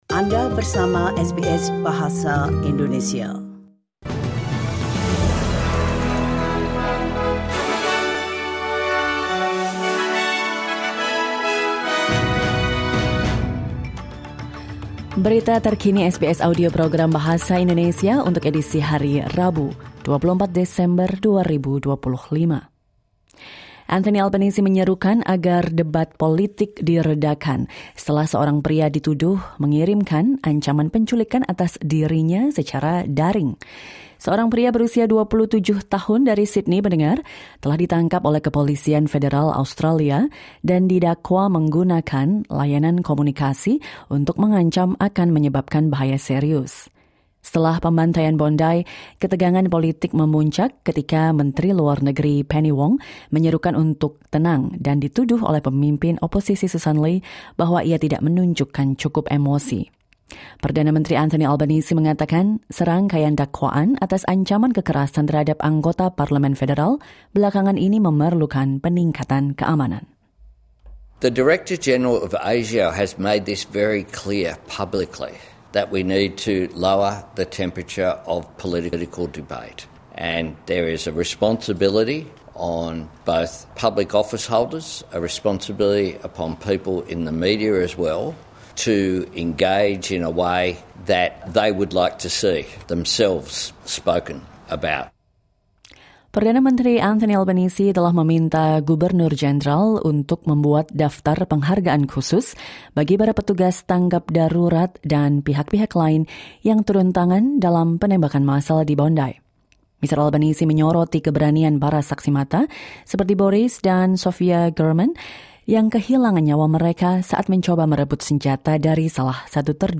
Latest News SBS Audio Indonesian Program - Wednesday 24 December 2025